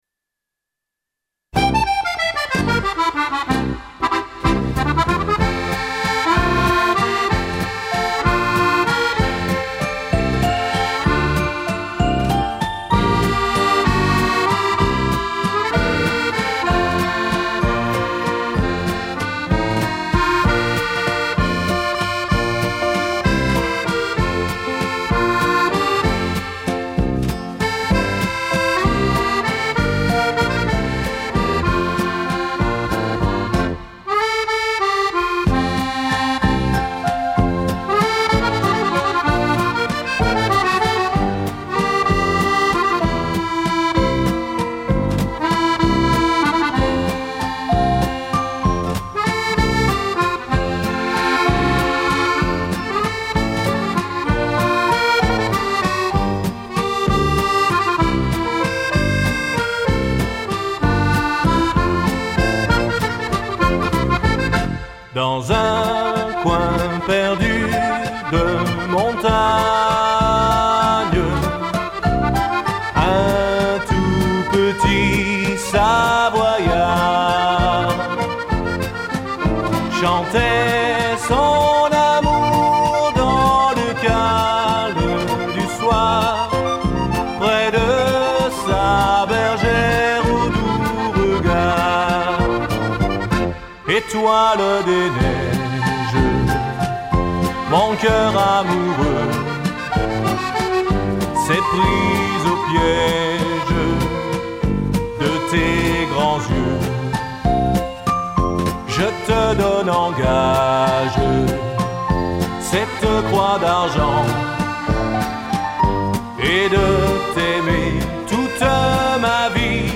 инструментальных версий